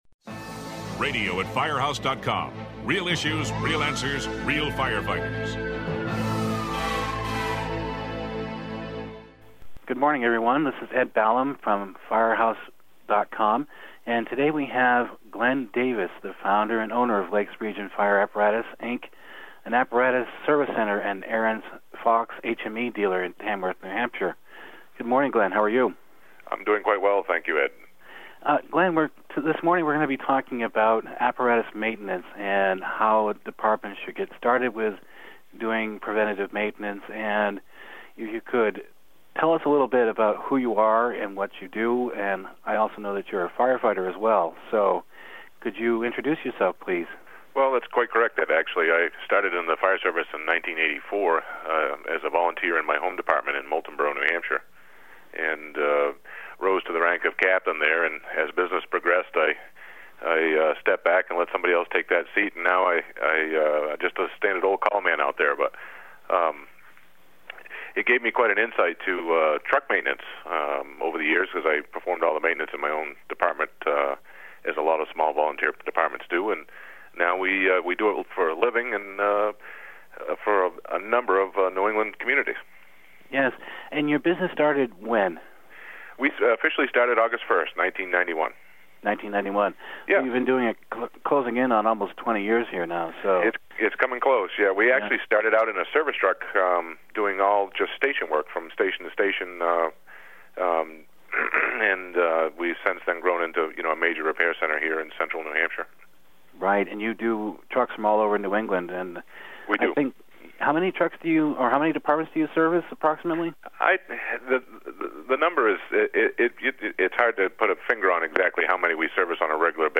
The two discuss the need to find a competent and trusted service center as part of a solid apparatus preventative maintenance program.